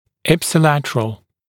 [ˌɪpsɪˈlætərəl][ˌипсиˈлэтэрэл]ипсилатеральный, относящийся к одной и той же стороне тела, односторонний